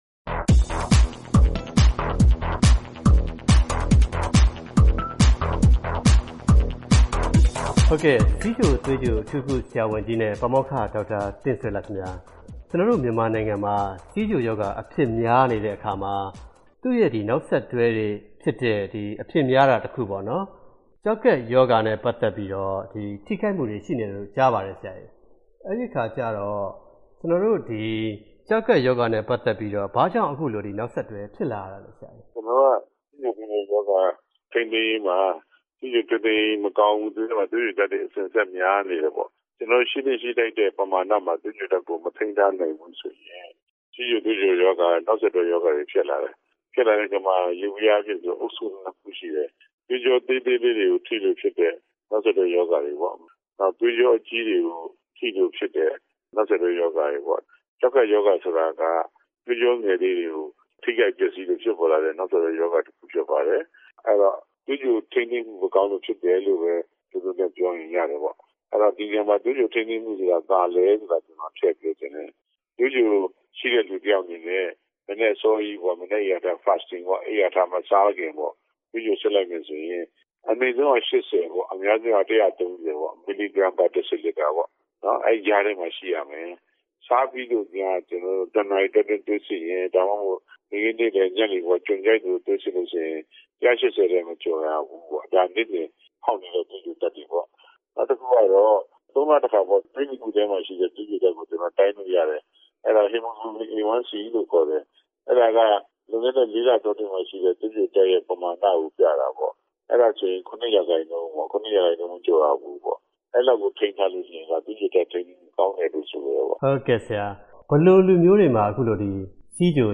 ဆက်သွယ်မေးမြန်း ဆွေးနွေးတင်ပြထားပါတယ်။